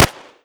Pistol.wav